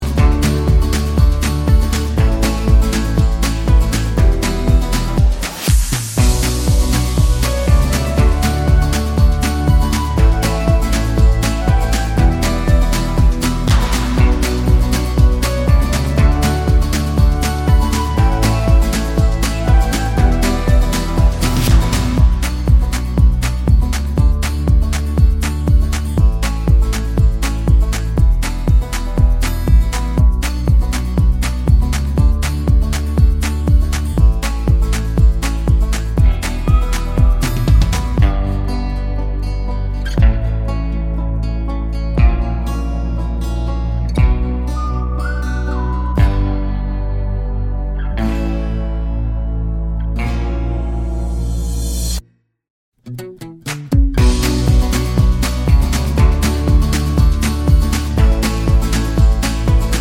For Solo Singer Pop